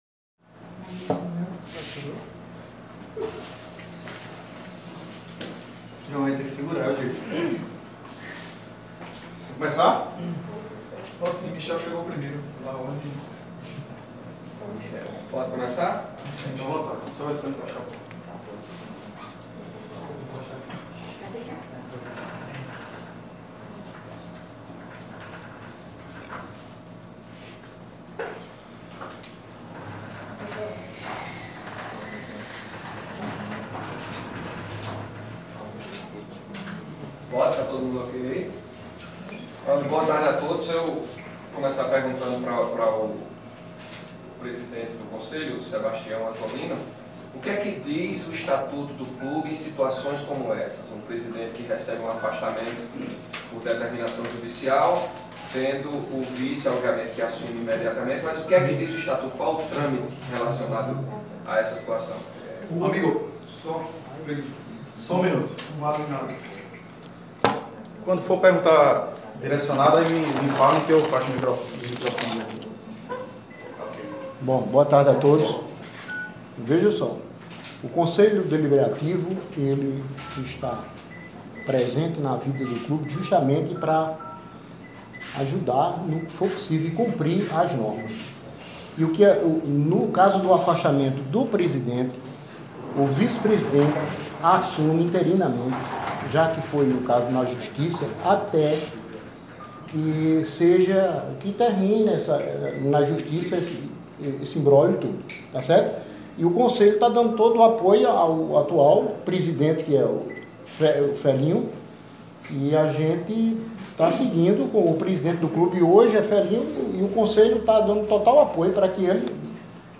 durante entrevista coletiva nesta quinta-feira.